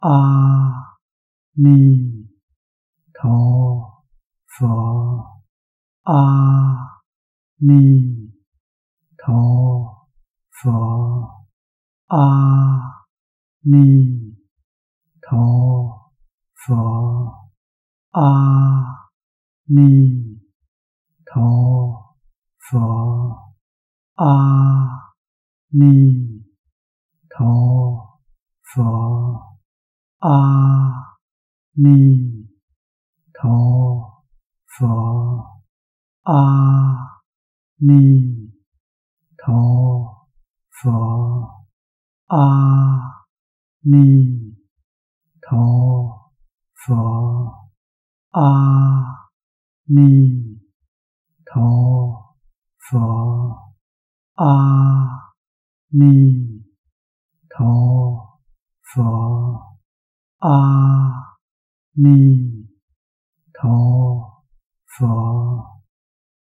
Niem-Phat-4-Chu-Cham-HT-Tinh-Khong-1p.mp3